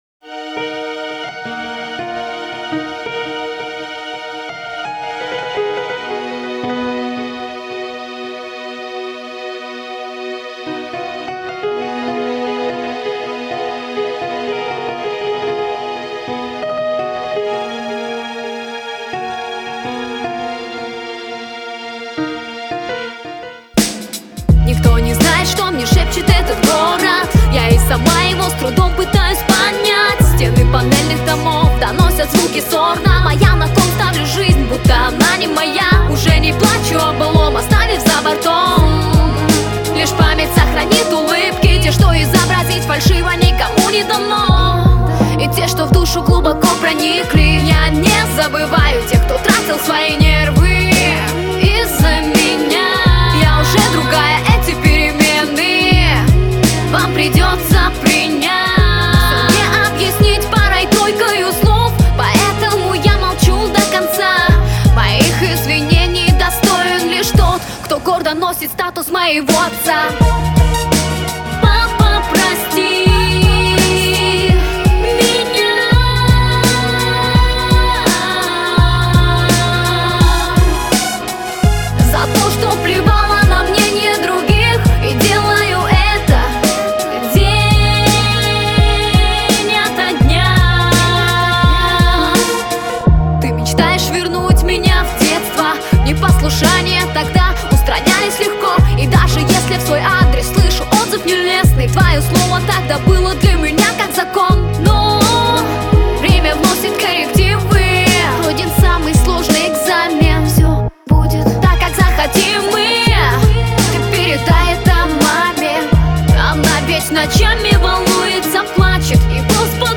Здорово сделано, красиво звучит